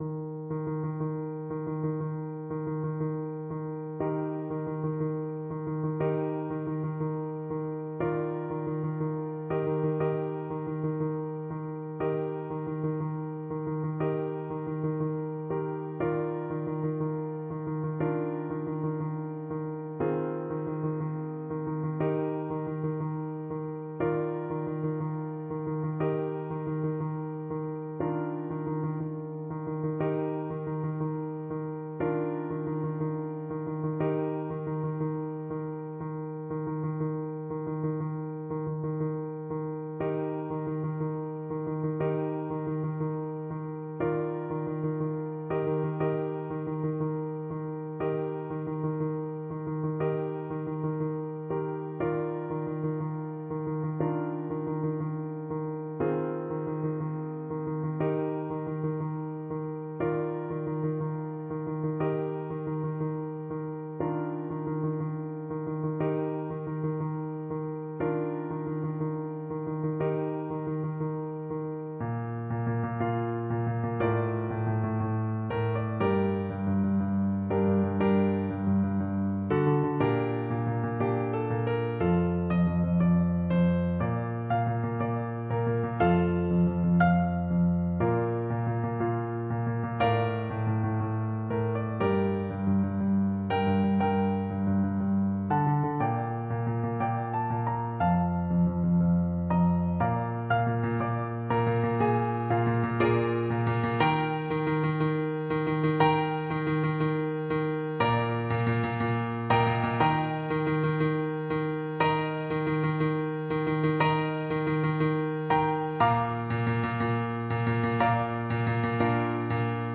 Trumpet
4/4 (View more 4/4 Music)
Nobilmente = c. 60
Arrangement for Trumpet and Piano
Eb major (Sounding Pitch) F major (Trumpet in Bb) (View more Eb major Music for Trumpet )
Traditional (View more Traditional Trumpet Music)